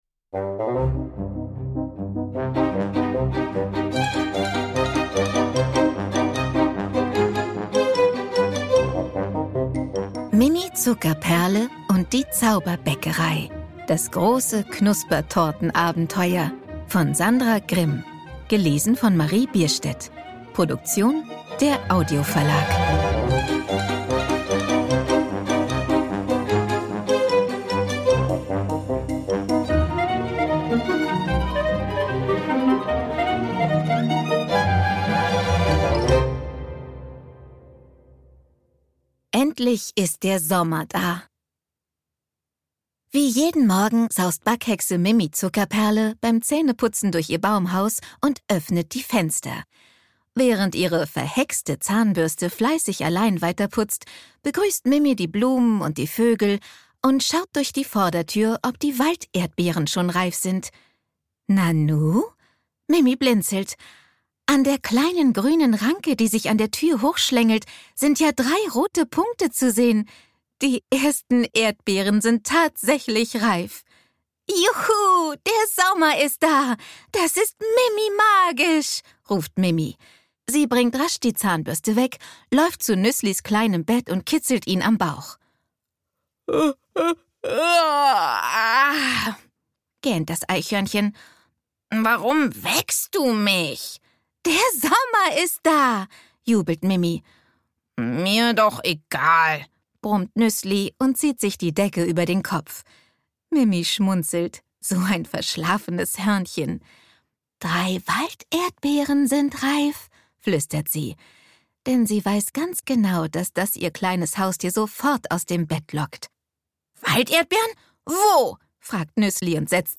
Ungekürzte Lesung mit Musik